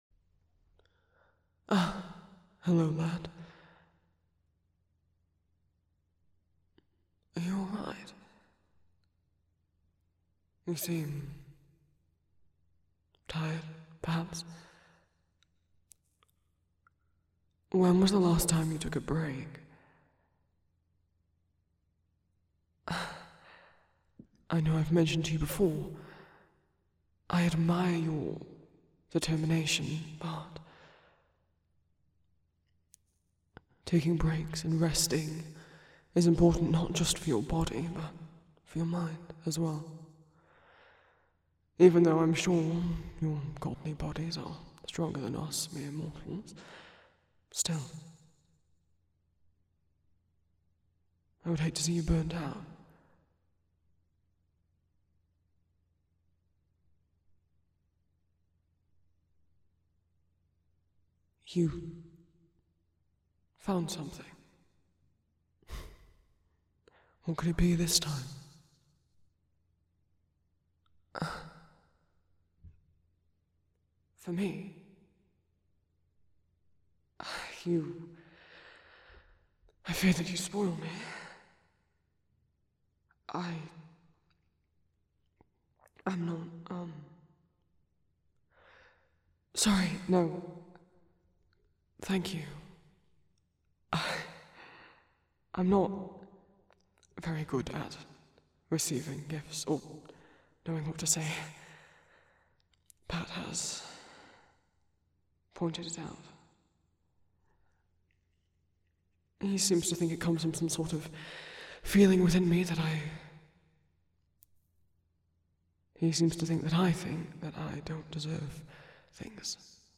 [M4M] [Established relationship - left a little ambiguous as to whether it's platonic or romantic] [Listener takes the place of Zagreus] [Sleep aid]